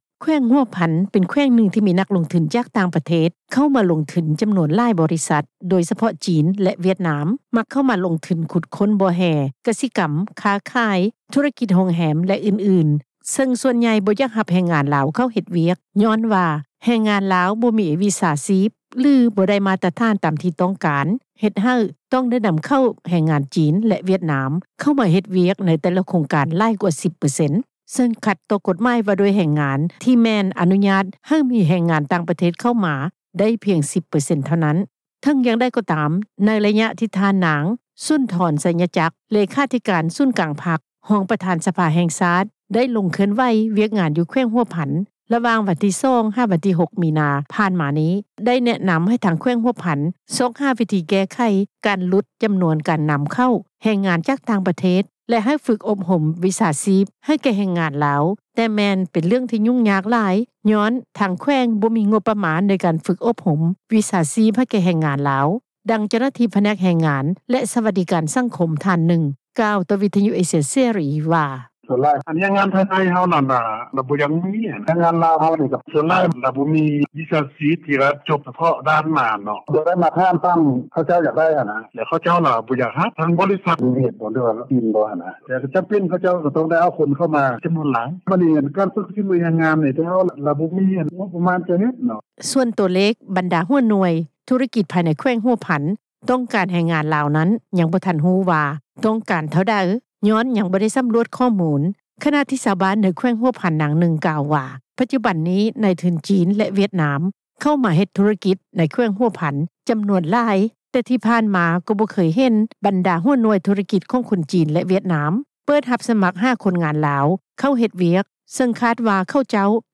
ພ້ອມກັນນີ້ ຊາວບ້ານໃນແຂວງຫົວພັນ ອີກທ່ານໜຶ່ງ ກ່າວວ່າ ຄົນງານລາວພາຍໃນແຂວງຫົວພັນ ສ່ວນໃຫຍ່ ໄປຫາເຮັດວຽກ ຢູ່ຕ່າງເມືອງ ຕ່າງແຂວງ ເພາະສ່ວນຫຼາຍ ບໍລິສັດ ຂອງຈີນ ແລະຫວຽດນາມ ຈະນຳເຂົ້າຄົນຂອງປະເທດຕົນເອງ ເຂົ້າມາເຮັດວຽກເອງ ຍ້ອນສີມືເຮັດວຽກ ດີກວ່າຄົນງານລາວໃນແຂວງ:
ຂະນະດຽວກັນ ຊາວບ້ານໃນແຂວງຫົວພັນ ອີກນາງໜຶ່ງ ກໍກ່າວວ່າ ສ່ວນຫຼາຍແລ້ວ ຄົນງານ ພາຍໃນແຂວງຫົວພັນ ບໍ່ຄ່ອຍມີວຽກງານເຮັດ ຈຶ່ງຕ້ອງໄດ້ໄປຫາເຮັດວຽກ ຢູ່ຕ່າງແຂວງ ເປັນຕົ້ນນະຄອນຫຼວງວຽງຈັນ ຍ້ອນມີວຽກງານຫຼາຍ ກວ່າຢູ່ແຂວງຫົວພັນ: